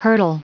Prononciation du mot hurtle en anglais (fichier audio)
Prononciation du mot : hurtle